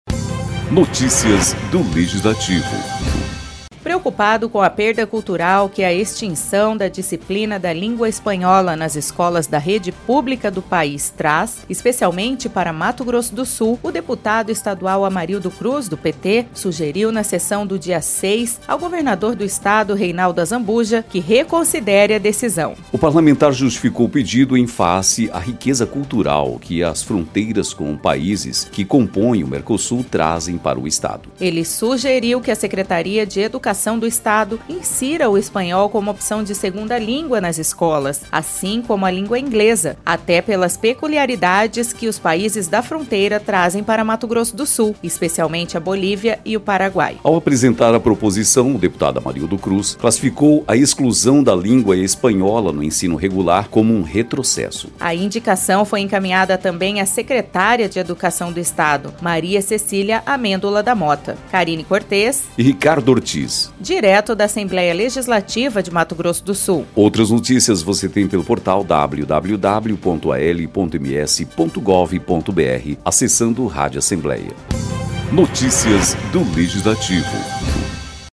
Locução